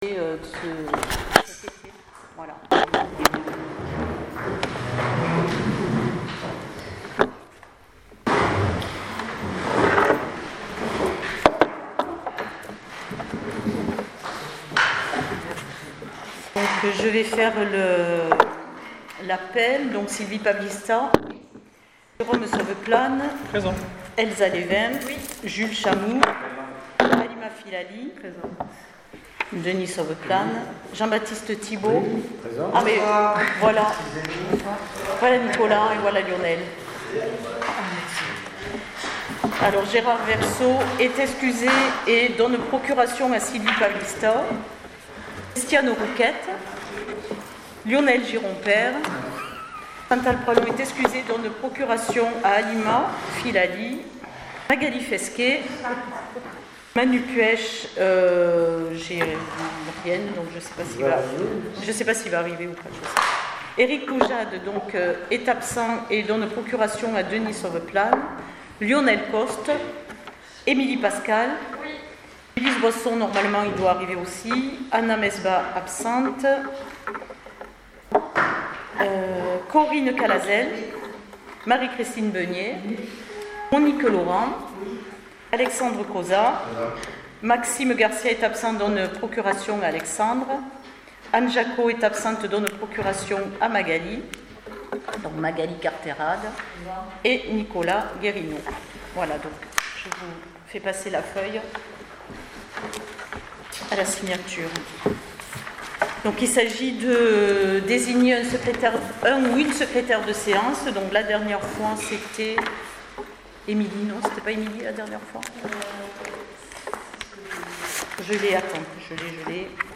PROCÈS VERBAL DU CONSEIL MUNICIPAL DU 23 SEPTEMBRE 2025